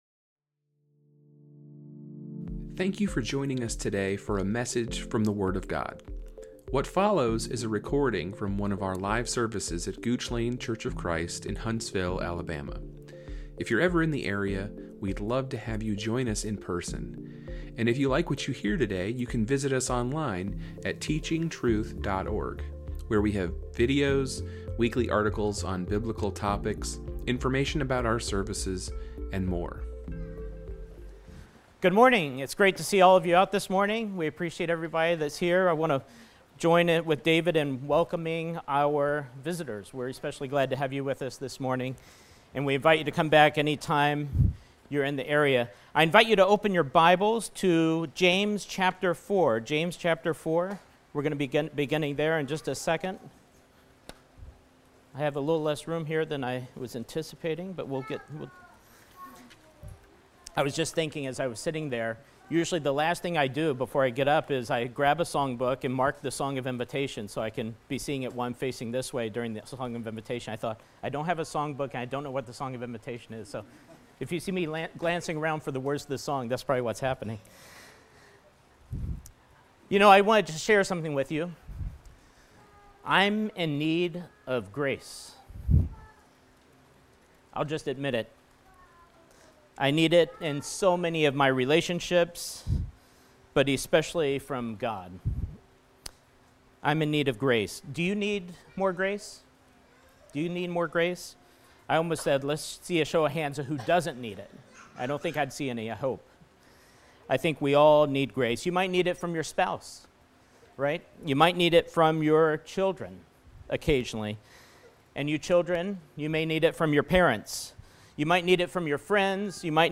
A sermon given on May 25, 2025.